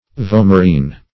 Vomerine \Vo"mer*ine\